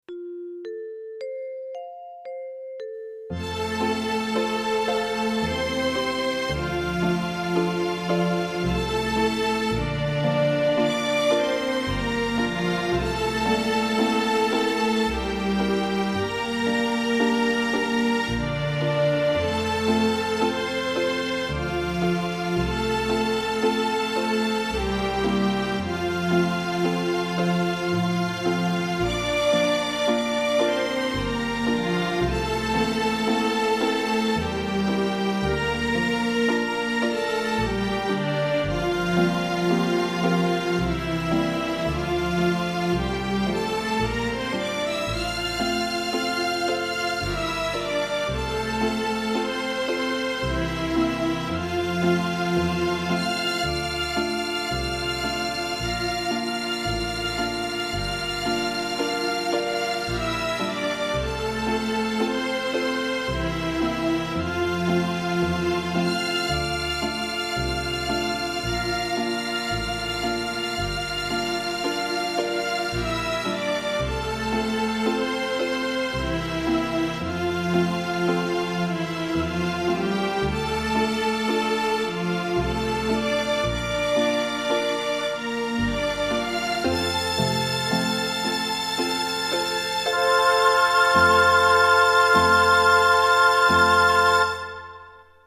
L'Intermezzo della Cavalleria rusticana di Pietro Mascagni è uno dei brani più intensi ed espressivi dell'opera.
Lo presentiamo in versione didattica per flauto.